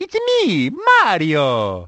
Mario introducing himself in Super Mario 64
SM64_Mario_It's-a_me_Mario.oga.mp3